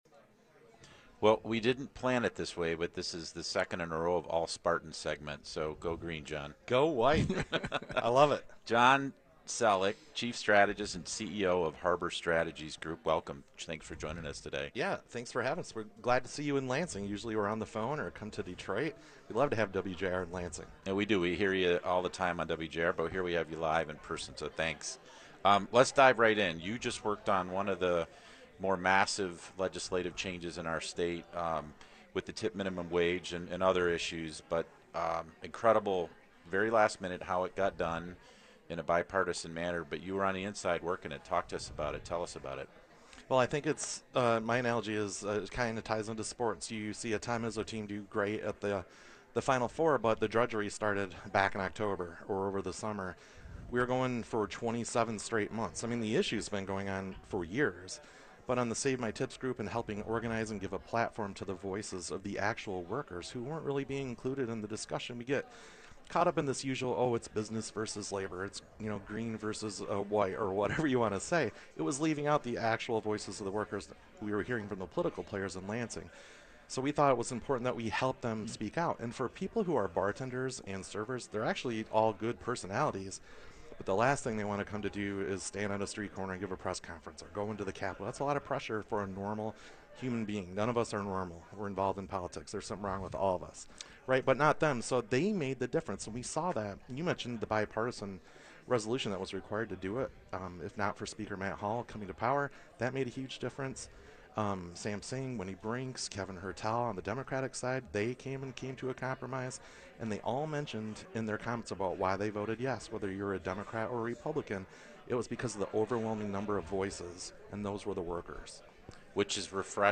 which broadcast live in Lansing prior to Gov.